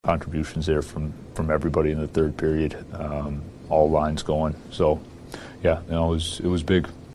Coach Dan Muse says the Pens quickly righted themselves after stumbling in the second period.